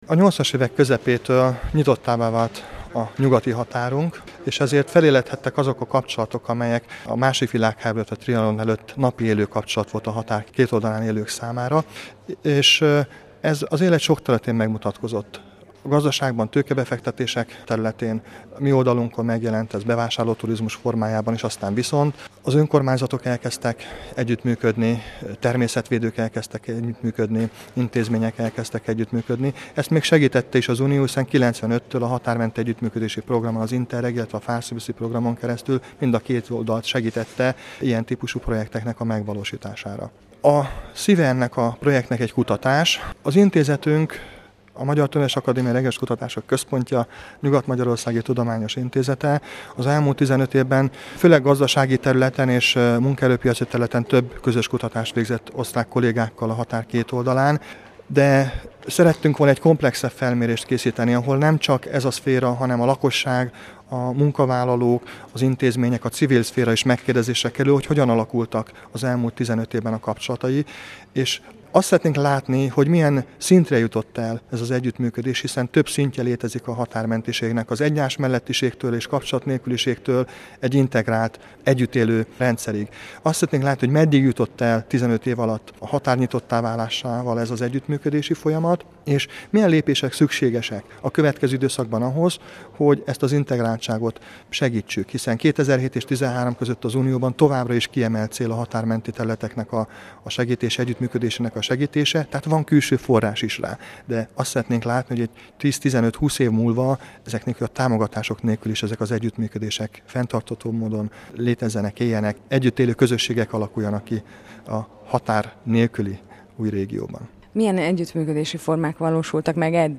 Magyar Rádió interjú